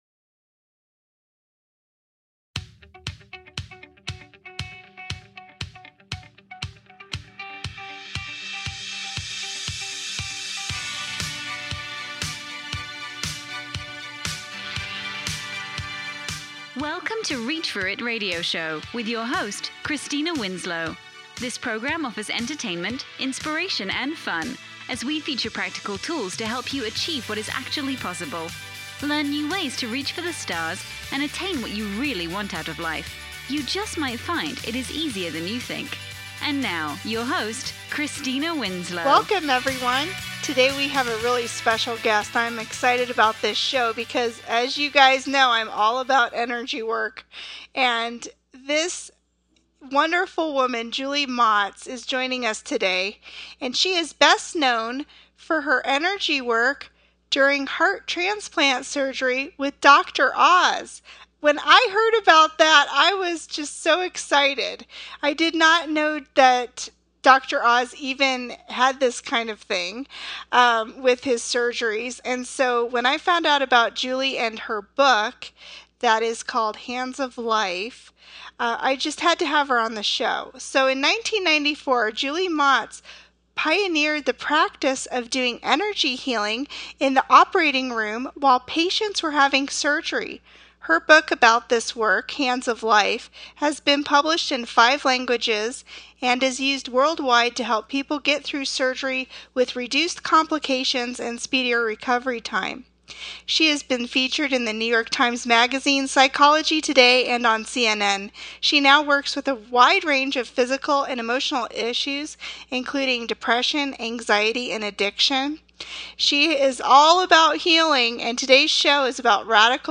This show is about Radical Healing and energy work.